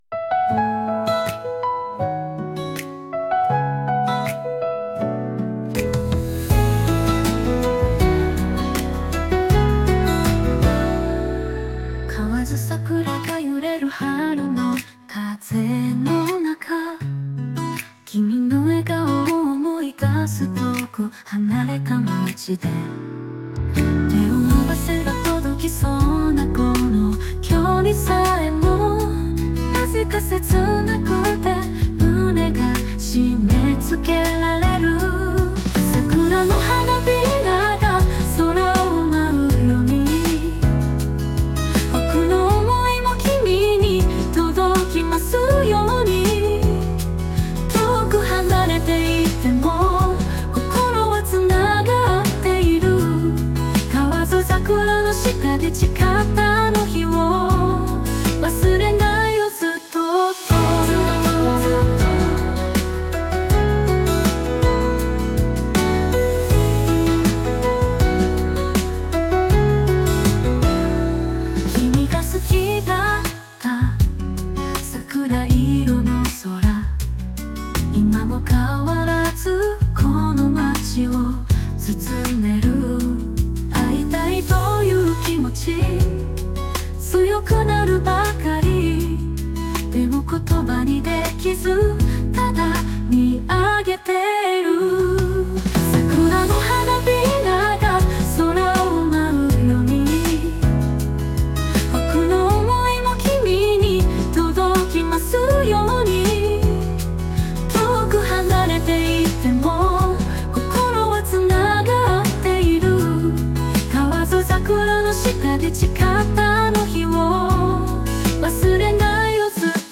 曲全般：SunoAI